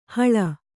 ♪ hūḷu